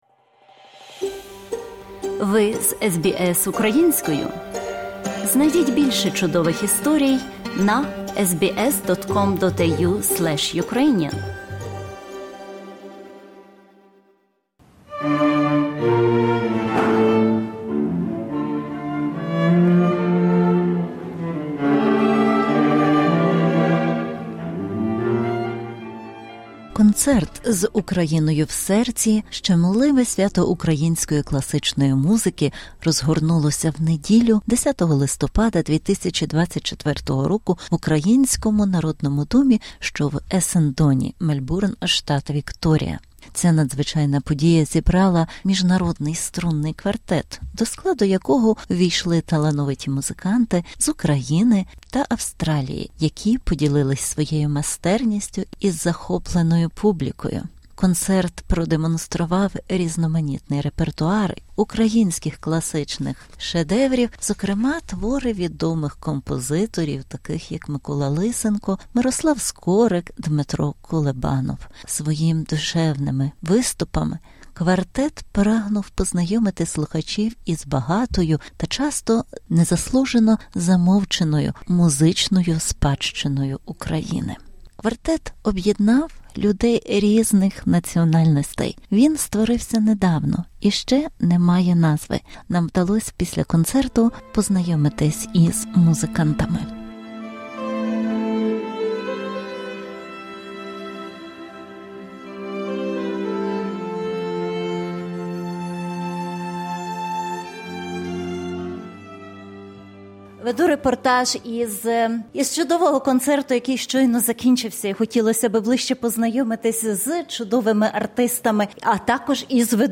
Цей репортаж та інтерв’ю висвітлює чудове виконання української класичної музики мультикультурним струнним квартетом.